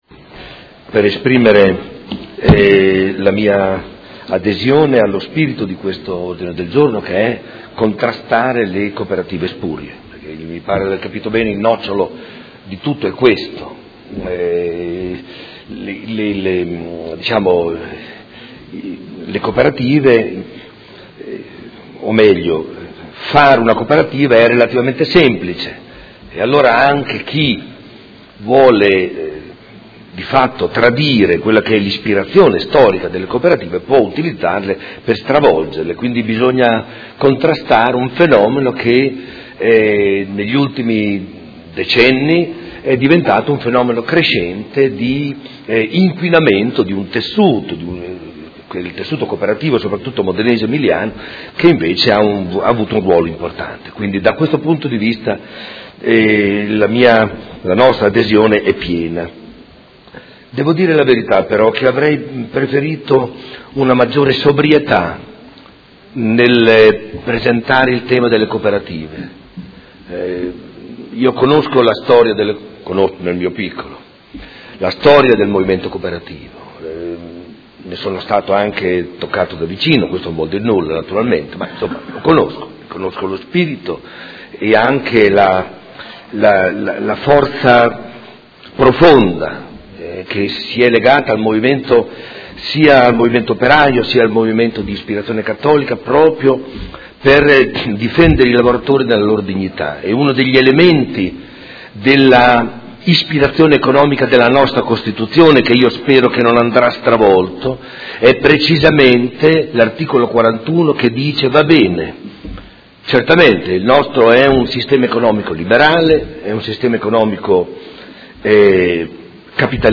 Seduta del 6 ottobre. Ordine del Giorno presentato dai Consiglieri Morini, Venturelli, Forghieri, Fasano, Pacchioni, Stella, Di Paova, Malferrari, Poggi, Lenzini e Trande (P.D.) avente per oggetto: La Cooperazione sociale, modello imprenditoriale che garantisce coesione nella Città. Dibattito